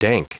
Prononciation du mot dank en anglais (fichier audio)
Prononciation du mot : dank